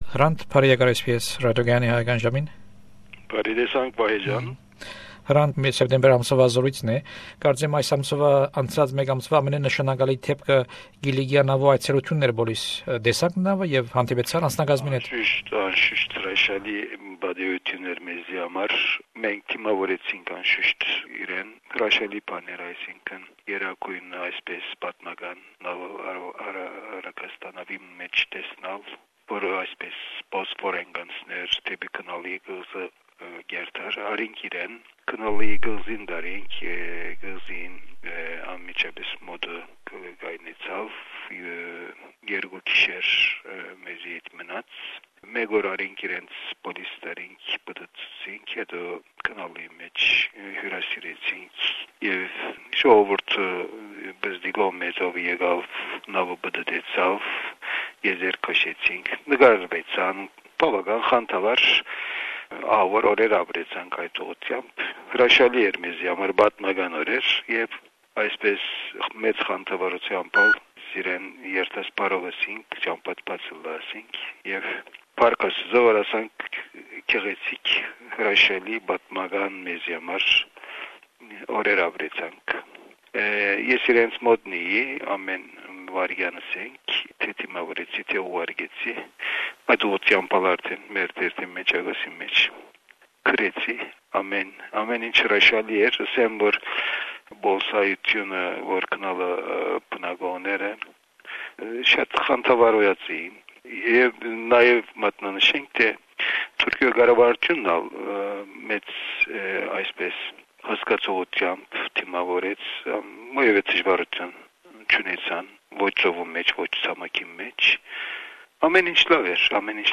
Հրանդ Տինքի յիշատակին: Սոյն հարցազրոյցը արձանագրուած է Սեպտեմբեր 2004ին: